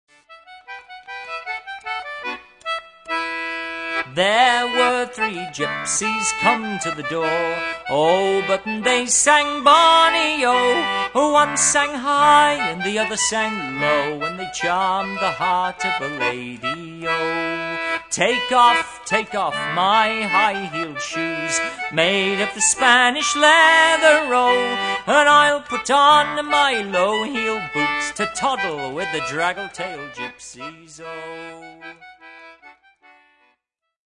A Pandora's Box of English Folk Songs
Recorded and mixed at Soundesign, Brattleboro VT.
We give the tune as it is used in Bampton for a morris jig.